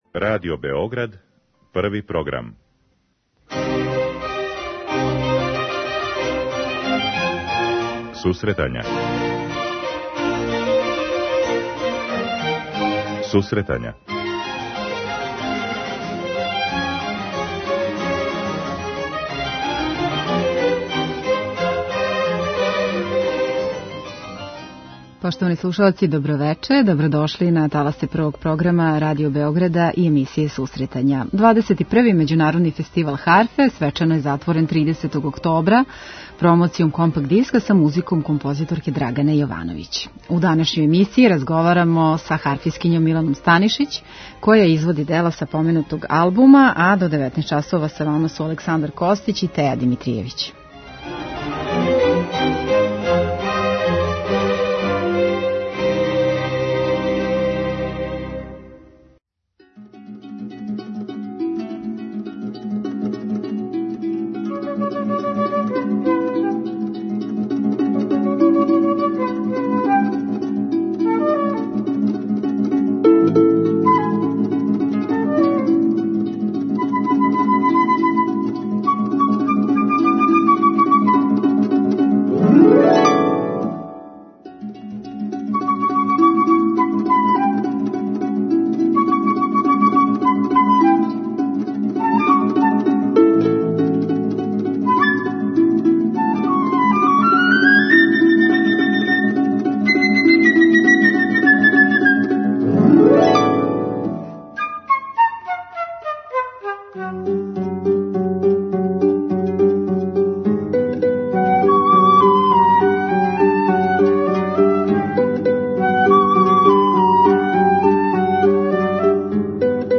Музика за харфу Драгане Јовановић